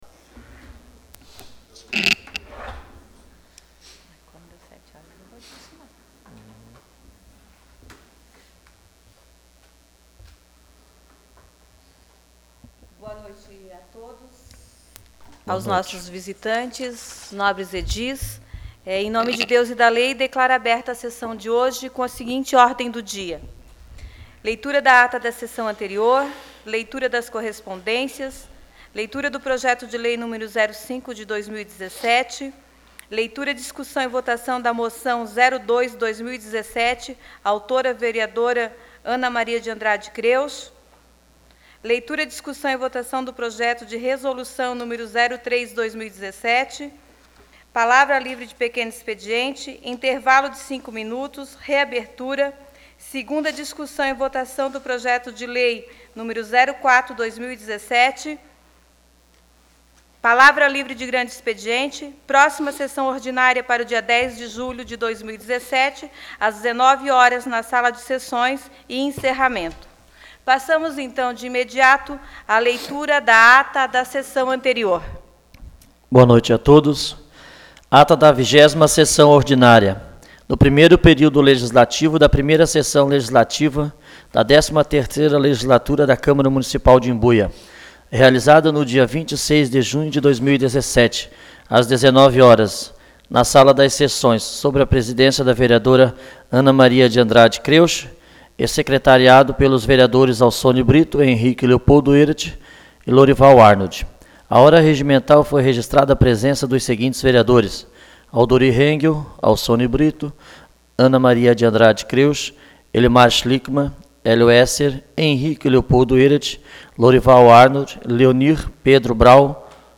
Domínio .leg.br Sessão Ordinária nº21/2017 Sessão Ordinária 02/2019